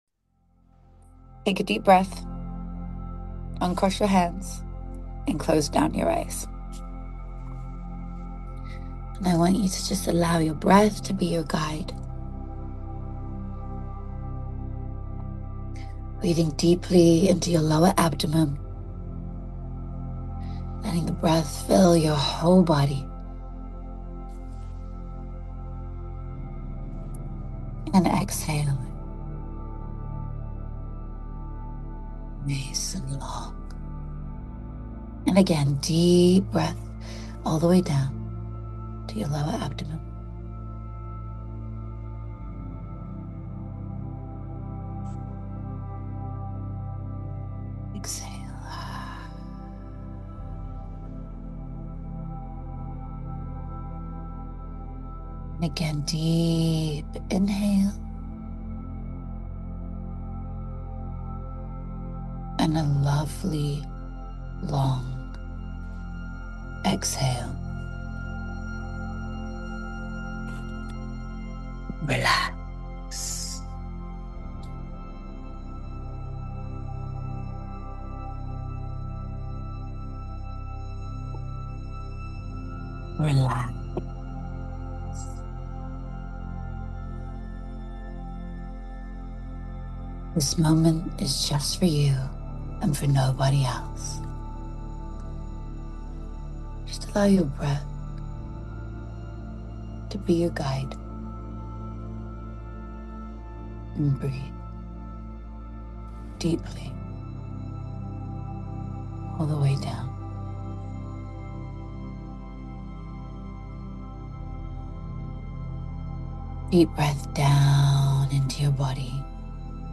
✨ This guided New Year Visualization Meditation is designed to help you step into your future self, activate belief, and emotionally rehearse the success, love, and abundance y. Podcast links by Plink.